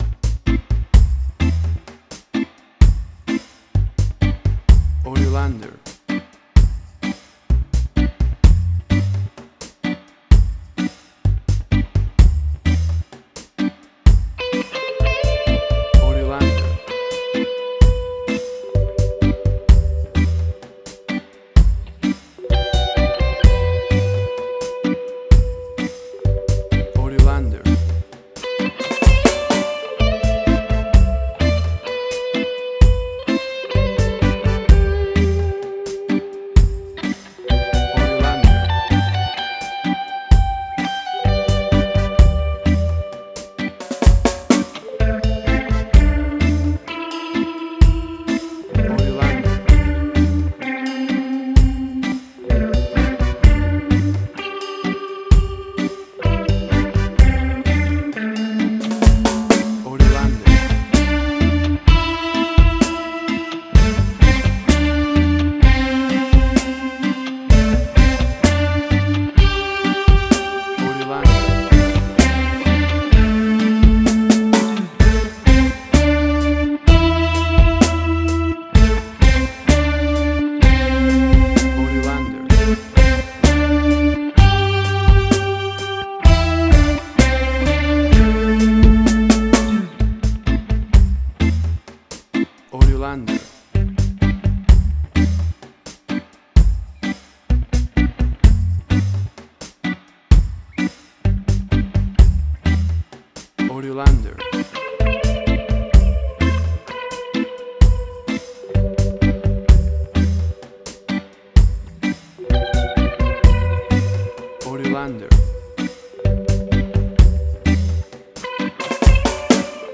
Reggae caribbean Dub Roots, old school reggae
Tempo (BPM): 64